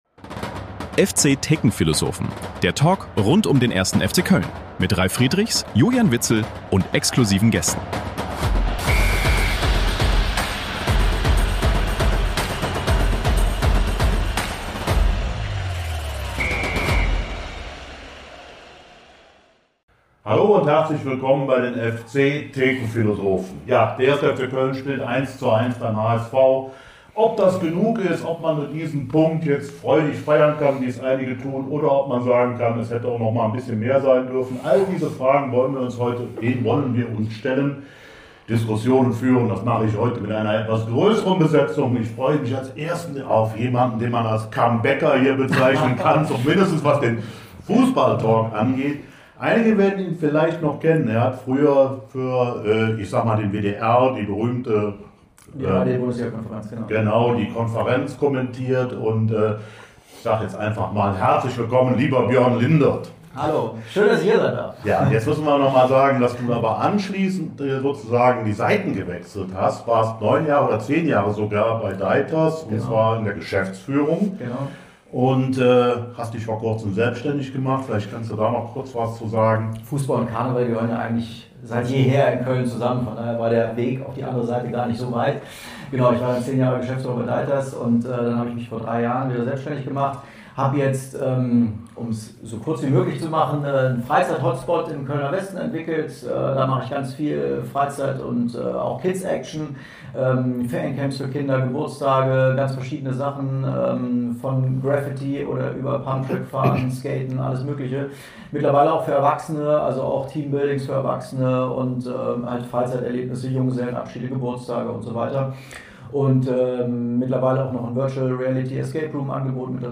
Die allgemein schwierige Lage lässt keine andere Schlussfolge zu, das kommende Derby gegen Mönchengladbach muss nun zwingend gewonnen werden. Was ist unser Pfund im Abstiegskampf, was macht Hoffnung und was muss sich nun ganz dringend ändern? PS: Wir erhalten einzelne Rückmeldungen, das der Ton dieses Mal gelegentlich (zu Beginn) nicht optimal klingt. Wir nutzen die gleichen Mikros wie immer, aber da hat wohl was an der Einstellung nicht ganz gepasst. Wir bitten, das zu entschuldigen, es wird im Verlauf des Talks dann besser.